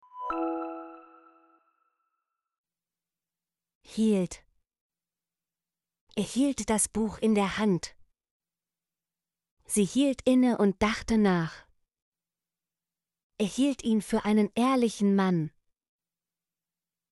hielt - Example Sentences & Pronunciation, German Frequency List